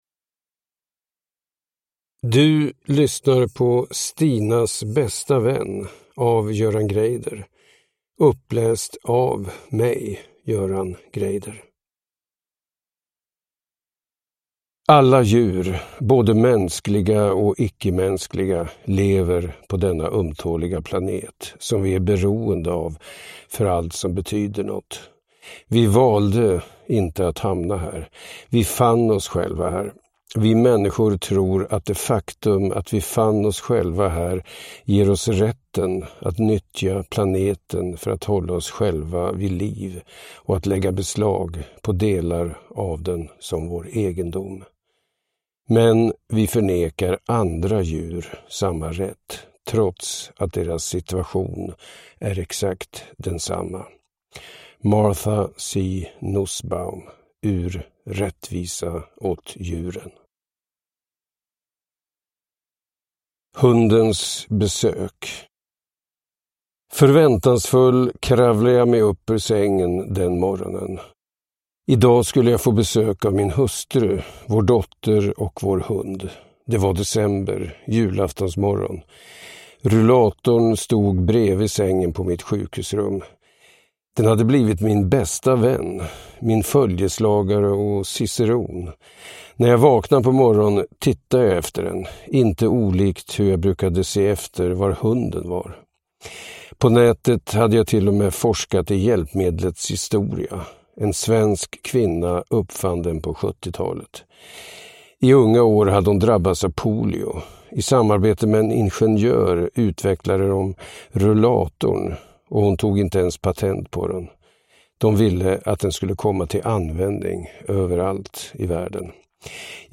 Uppläsare: Göran Greider
Ljudbok